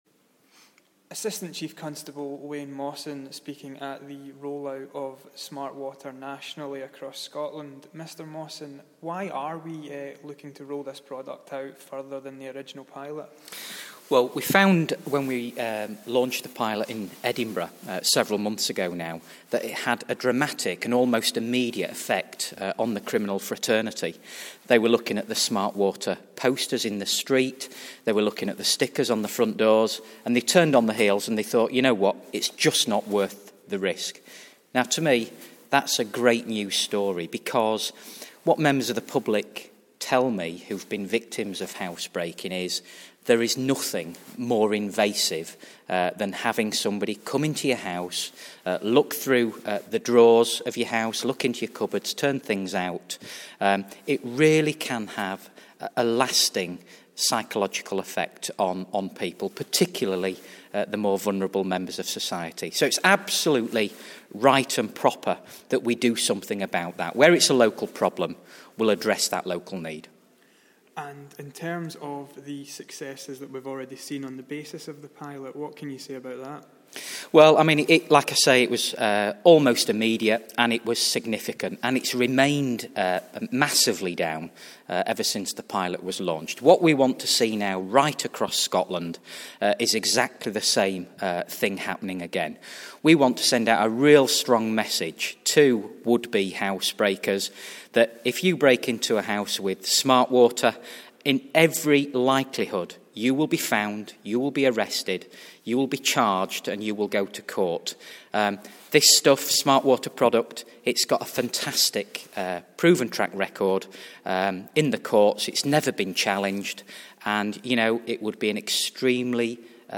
At the national roll out of Police Scotland's SmartWater initiative Assistant Chief Constable Wayne Mawson highlights the benefits to Scotland's communities.